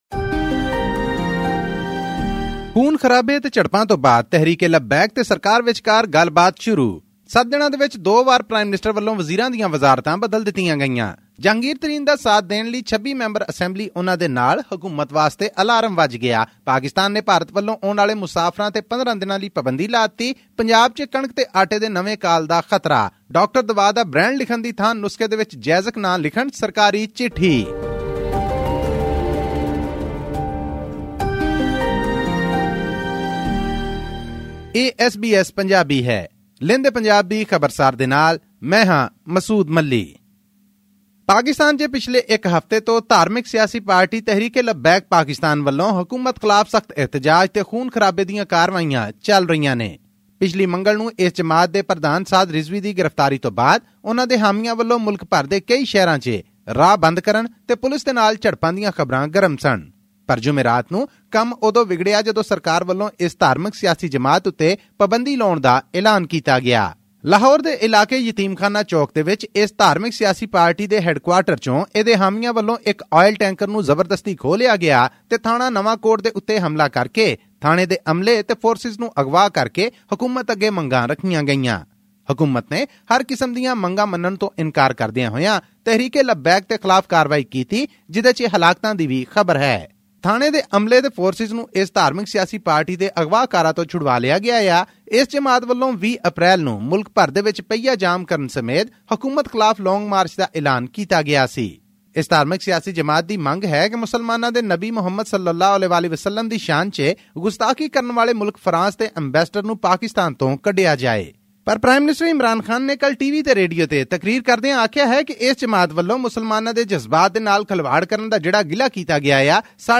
full audio report